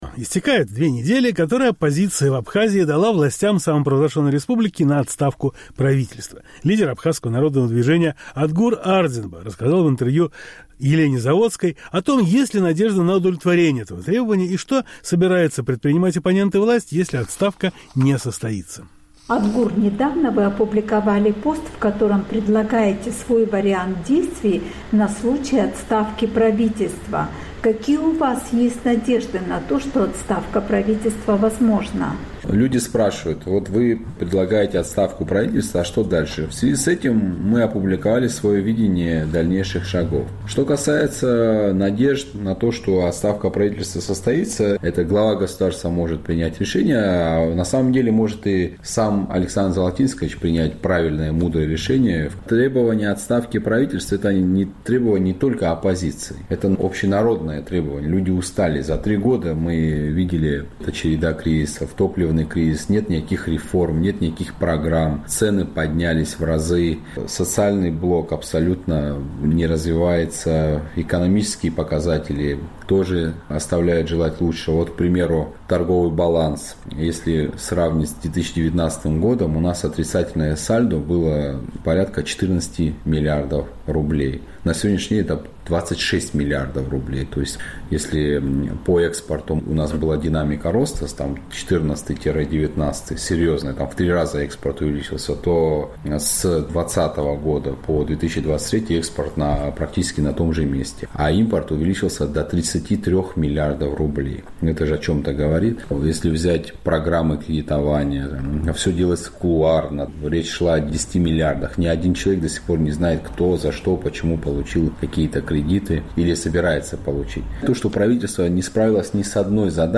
Истекают две недели, которые оппозиция дала властям Абхазии на отставку правительства. Лидер Абхазского народного движения Адгур Ардзинба рассказал в интервью «Эху Кавказа» о том, есть ли надежда на удовлетворение этого требования и что собираются предпринимать оппоненты власти, если отставка не...